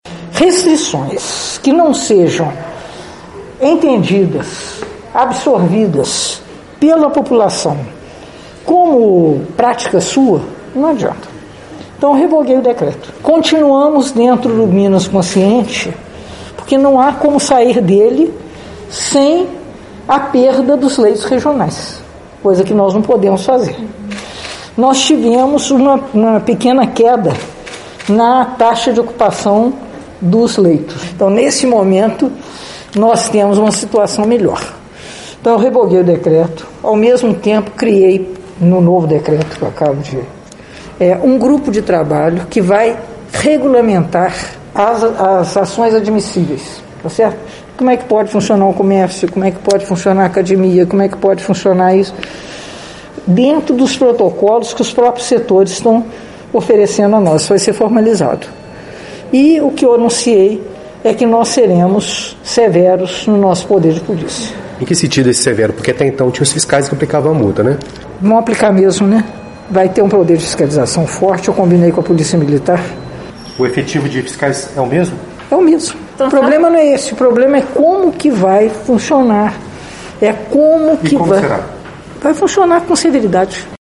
Em entrevista à imprensa, a prefeita afirmou que será criado um grupo de trabalho para regulamentar o funcionamento dos setores, além de fiscalização mais rígida.